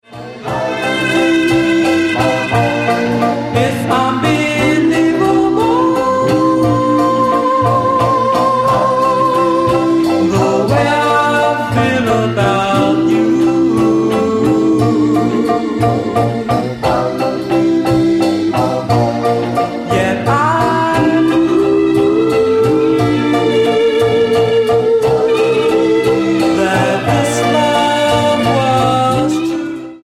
Doo Wop